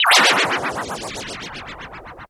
missile.mp3